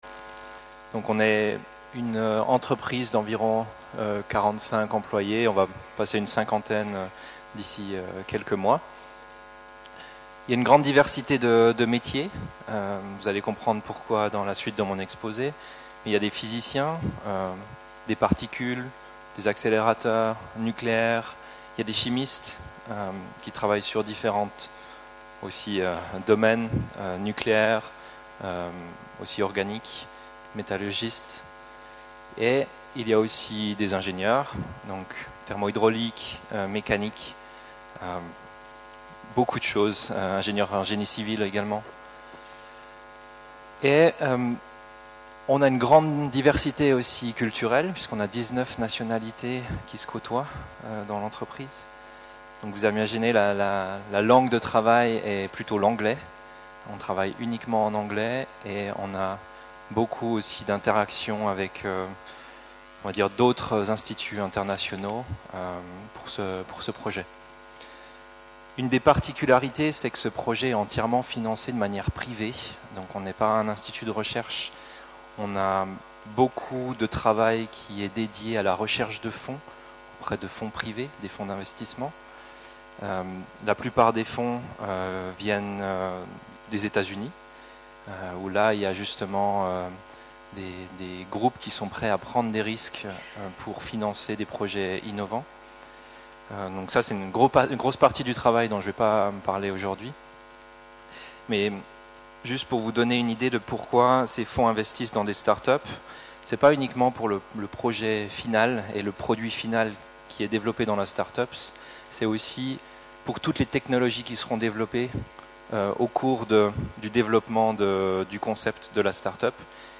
Enregistrement audio de la conférence